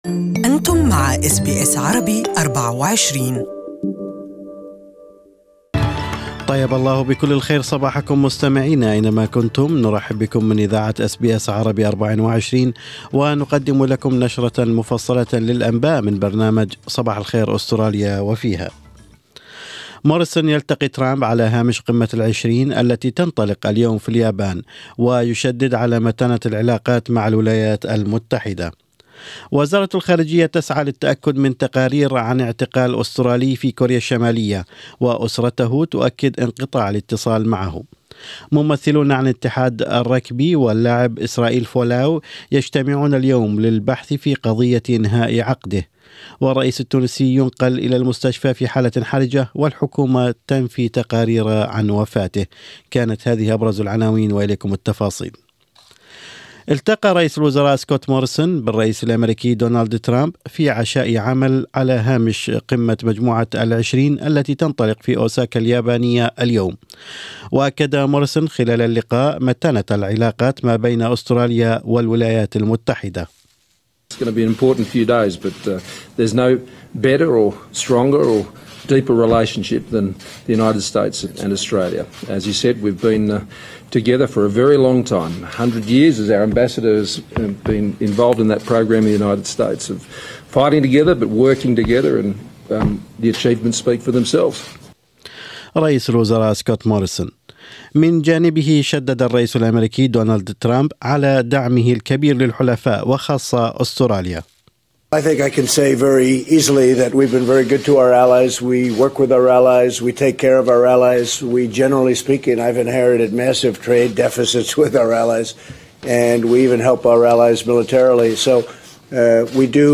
أخبار الصباح: فولاو يبدأ اليوم معركته القضائية ضد رغبي استراليا مطالبا بالاعتذار